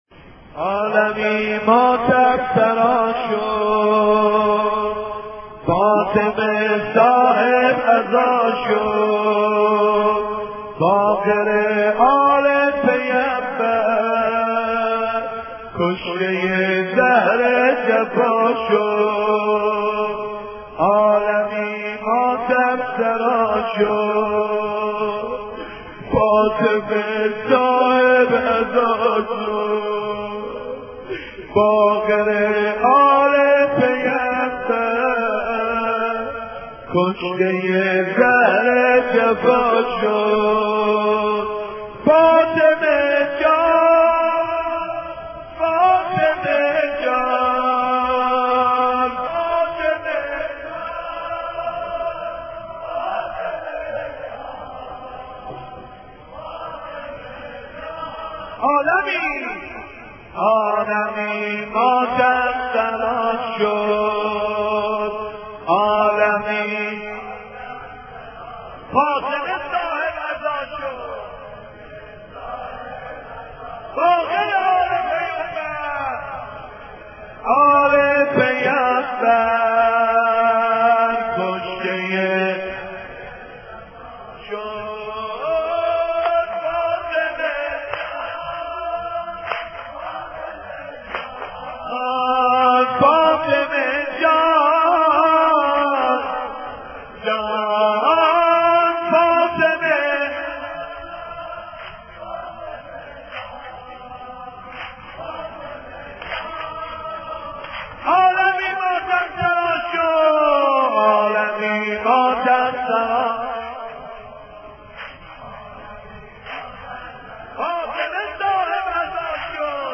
دانلود مداحی شهادت امام باقر